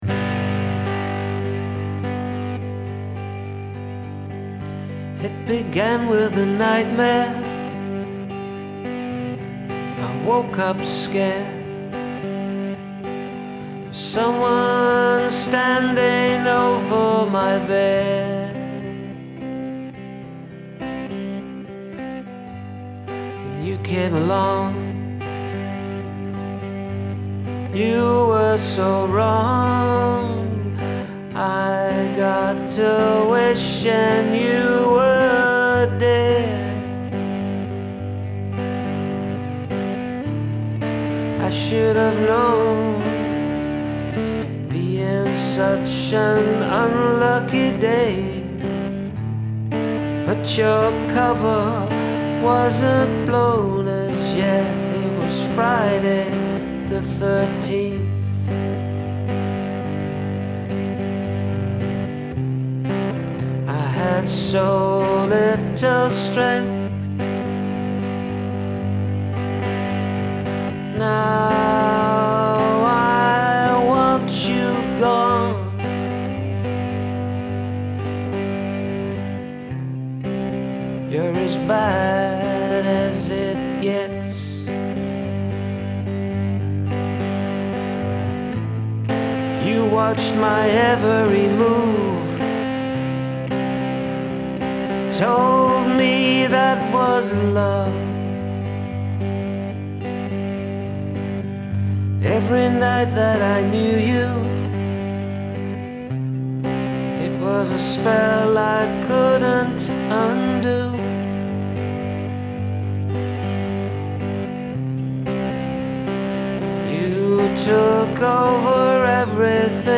Good vocal and playing to tell the story!
Ooo, this is wonderfully, weirdly creepy! I love it when you hear a song that sounds upbeat until you pay attention to the lyrics!
Very 90's